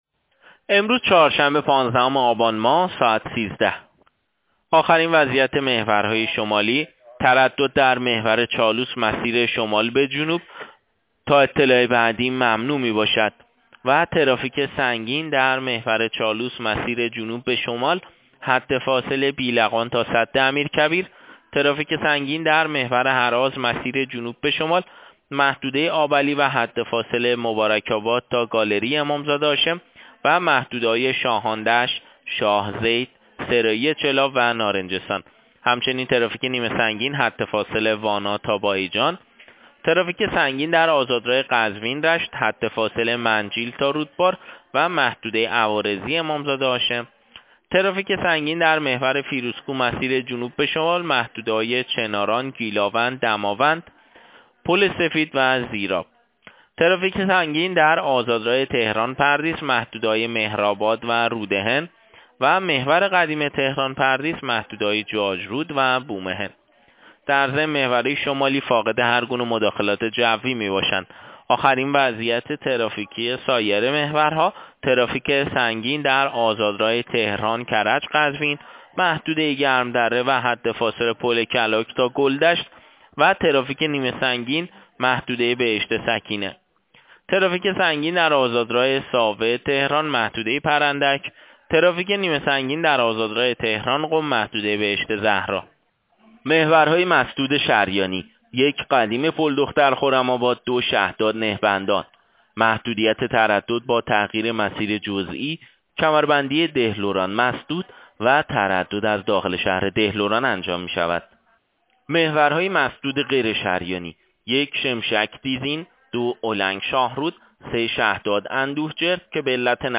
گزارش رادیو اینترنتی وزارت راه و شهرسازی از آخرین وضعیت ترافیکی جاده‌های کشور تا ساعت ۱۳ پانزدهم آبان ۱۳۹۸/ترافیک سنگین در محورهای شمالی/ ممنوعیت تردد در مسیر شمال به جنوب محور چالوس